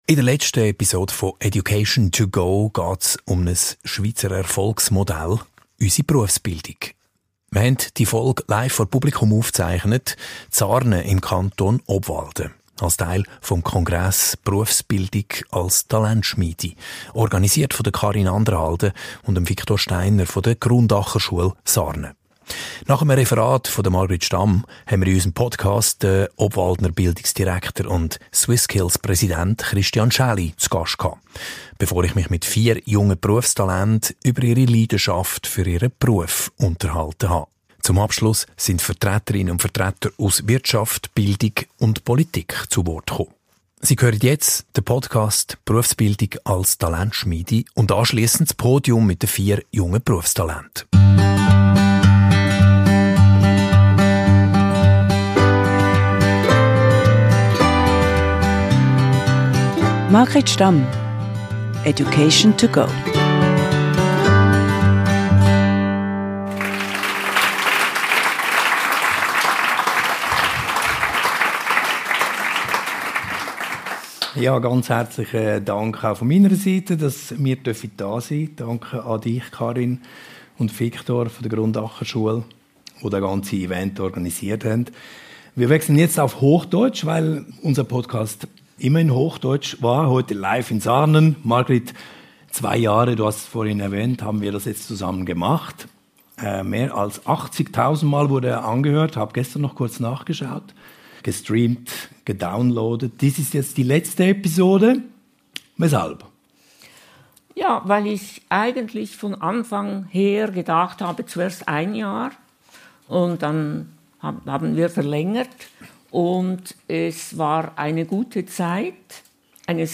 Den abschliessenden dritten Teil bildete das Podium mit Gästen aus Wirtschaft, Bildung und Politik. Hier können Sie die beiden Aufzeichnungen des ersten und zweiten Podiums hören.